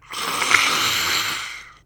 Zombie Voice Pack - Free / Zombie Hiss
zombie_hiss_010.wav